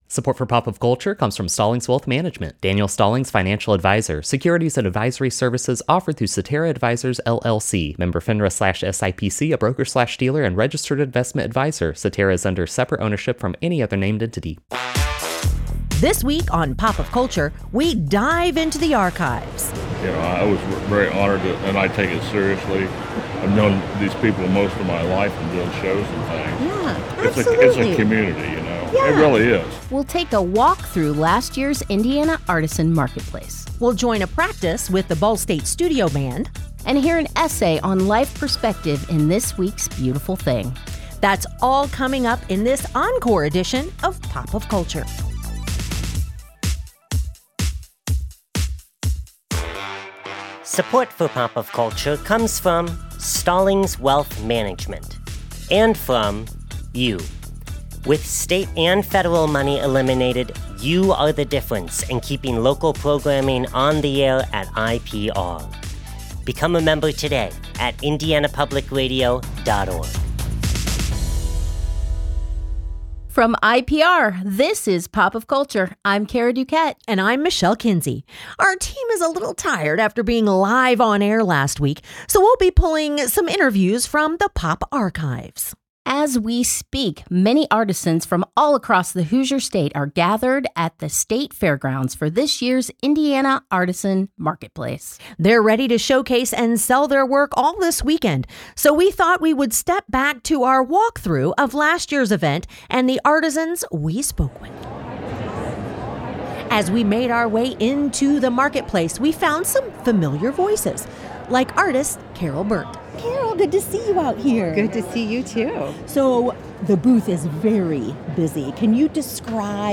So we’re listening back to some of favorite trips in past seasons: we find local artists on the floor of the 2025 Indiana Artisan Marketplace, we look to the skies with members of the Academy of Model Aeronautics, and The Ball State Studio Band brings us into their rehearsal.